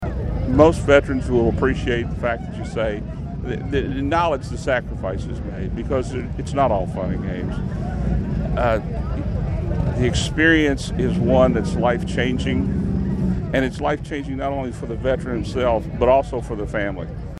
Patriotic pride filled the streets of downtown Hopkinsville Saturday as hundreds gathered for the city’s Veterans Day Parade — a heartfelt celebration of service and sacrifice.